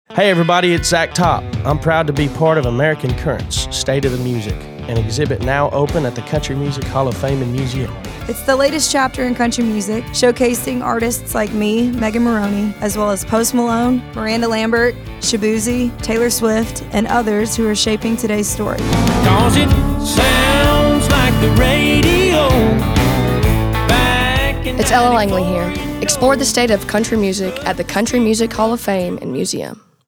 Produced Spot
American Currents: :30 Radio Spot